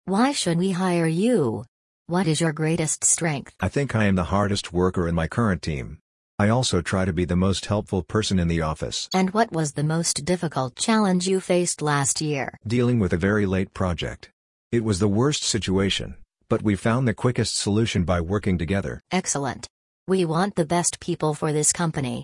🗣 Language in Use: The Interview
Dialogue-lesson-14.mp3